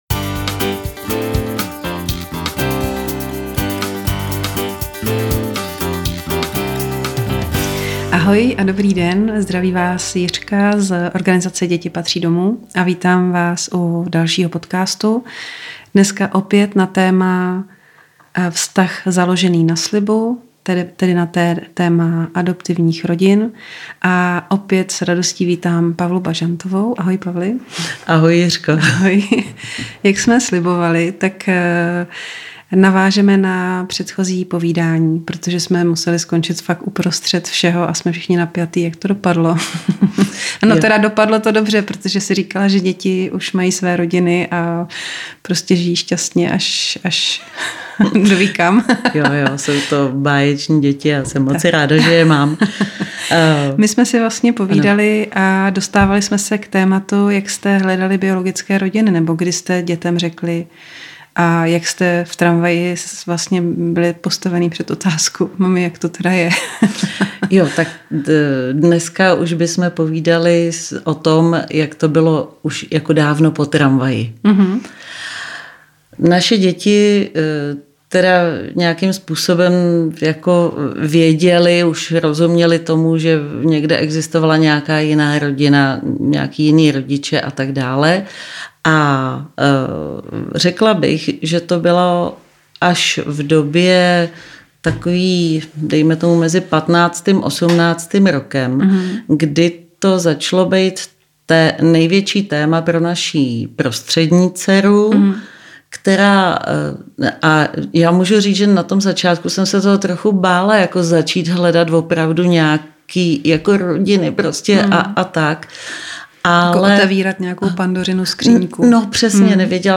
osvojitelka, pěstounka a lektorka. Jak je a není v adopci důležité hledat členy vlastní rodiny? Slib na začátku neznamená, že Ty můžeš všechno a já to musím vydržet. Otázka vyhoření.